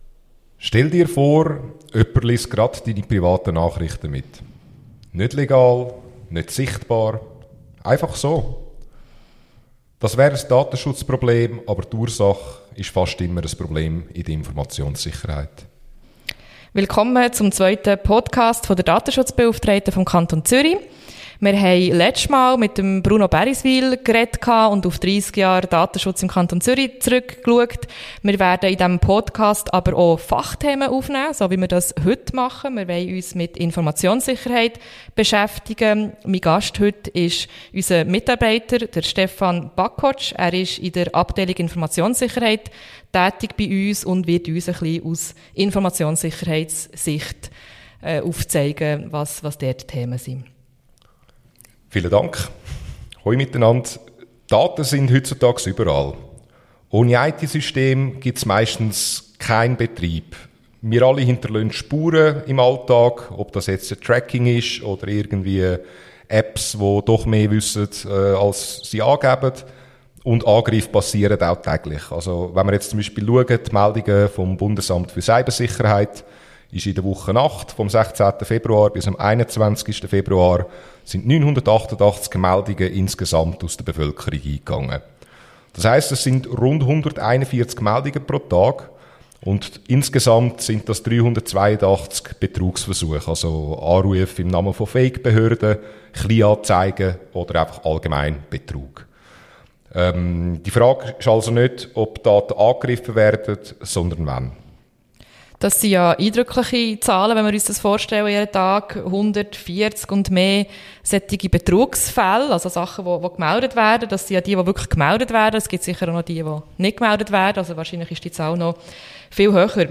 diskutiert die Datenschutzbeauftragte des Kantons Zürich, Dominika Blonski